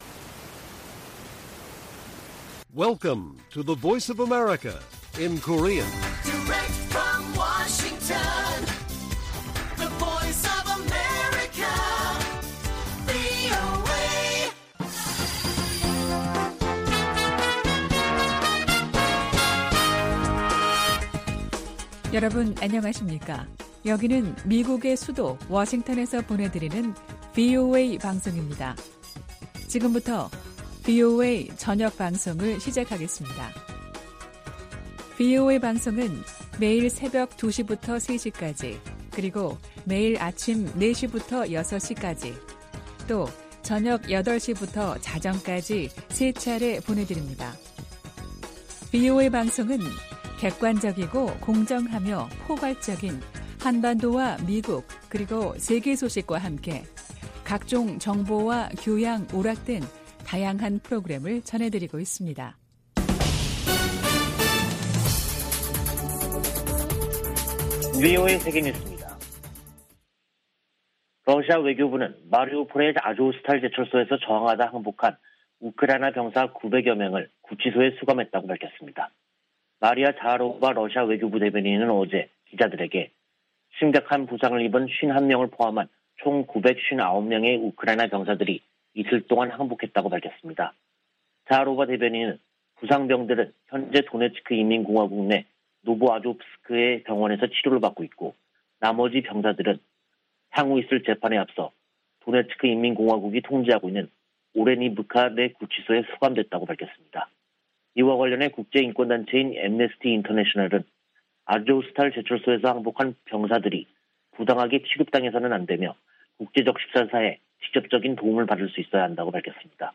VOA 한국어 간판 뉴스 프로그램 '뉴스 투데이', 2022년 5월 19일 1부 방송입니다. 제이크 설리번 백악관 국가안보보좌관은 조 바이든 미국 대통령의 아시아 순방 기간 북한이 대륙간탄도미사일(ICBM) 시험이나 핵실험을 감행할 가능성을 대비하고 있다고 밝혔습니다. 한국 국가정보원은 북한이 ICBM 발사 징후를 보이고 있고 핵실험은 준비를 마쳤다고 밝혔습니다.